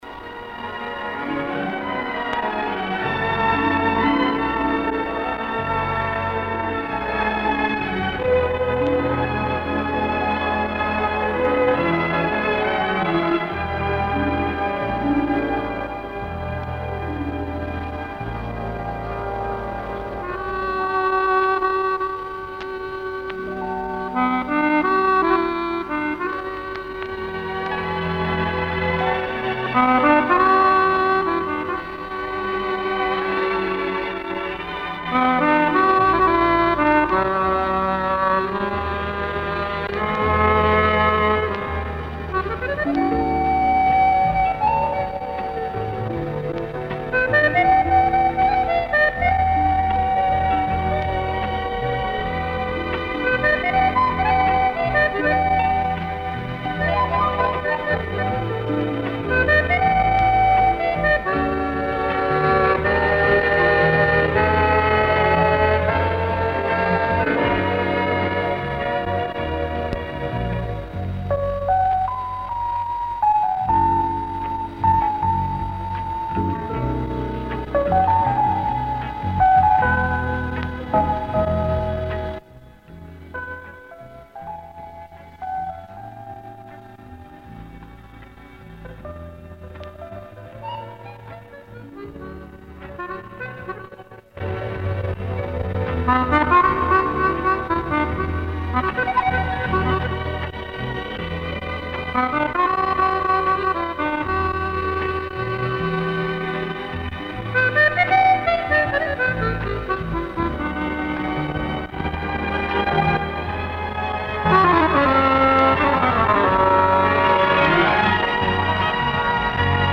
старая лента 19.3